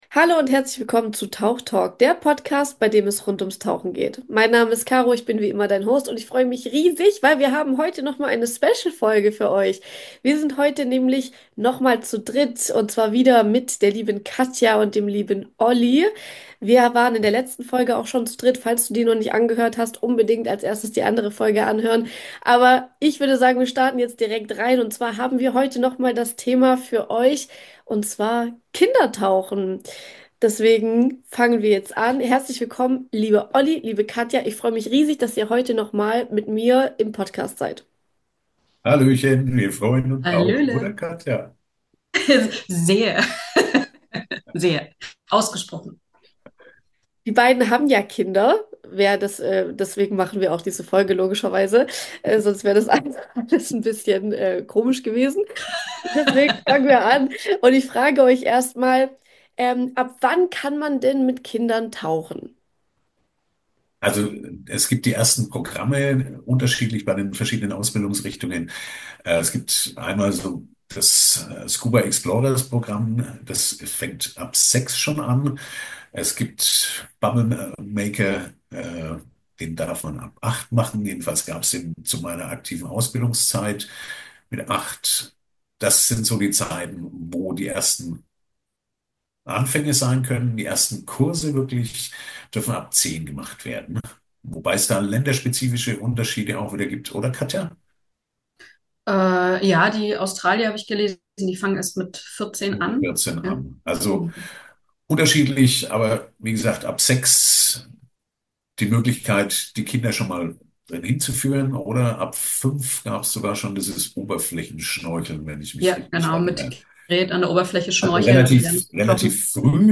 Tauchtalk: Der Podcast rund ums tauchen